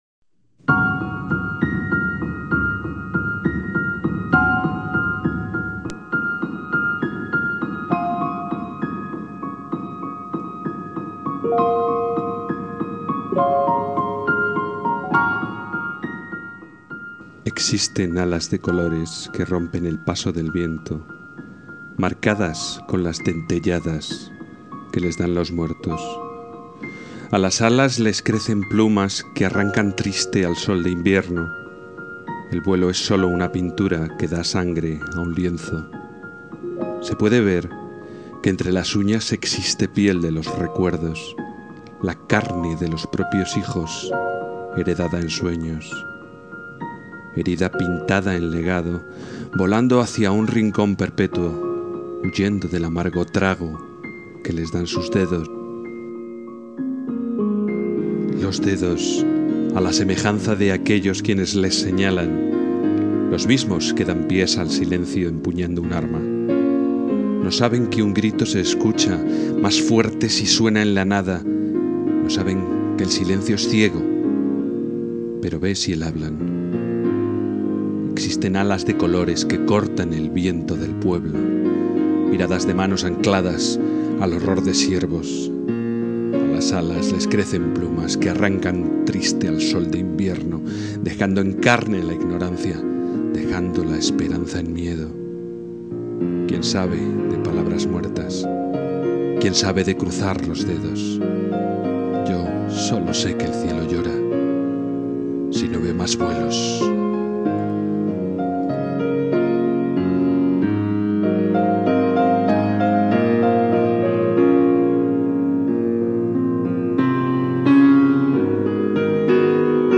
Inicio Multimedia Audiopoemas Alas de colores.